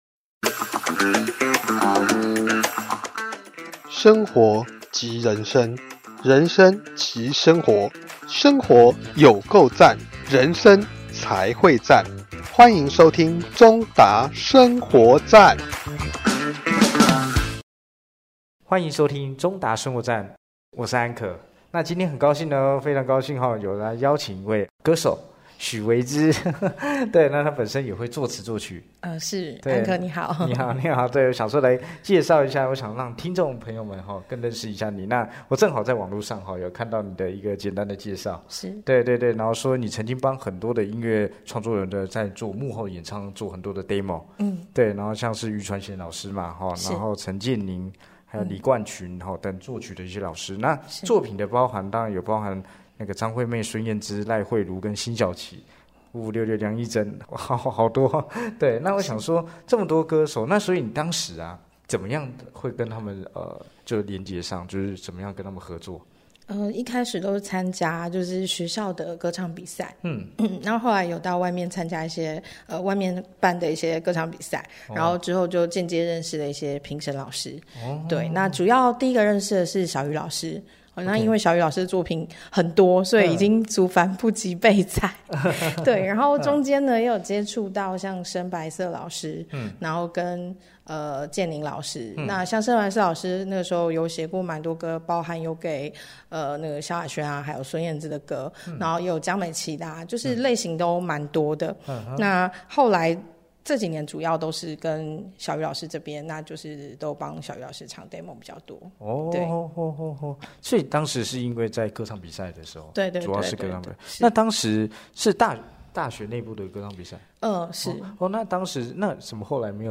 節目裡有生活點滴的分享、各界專業人物的心靈層面探析及人物專訪